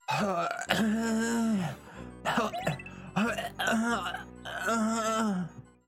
Play, download and share Lamentation original sound button!!!!
xdevmoan_Ynw6W82.mp3